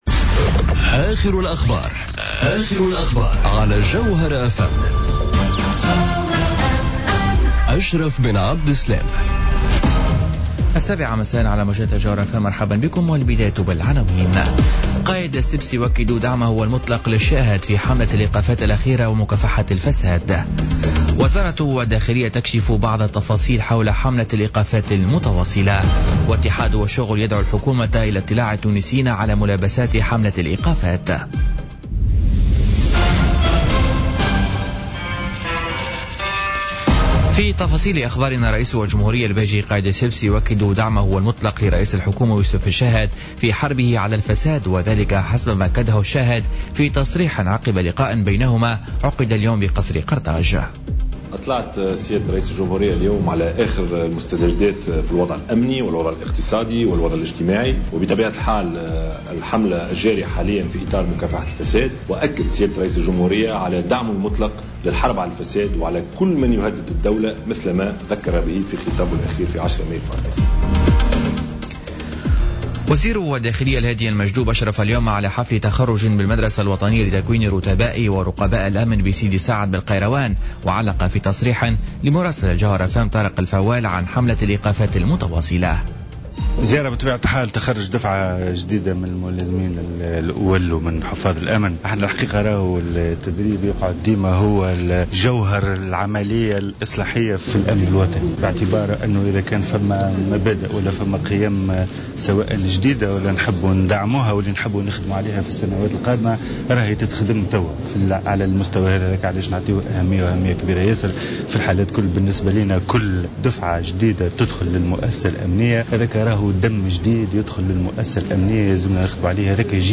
نشرة أخبار السابعة مساء ليوم الخميس 25 ماي 2017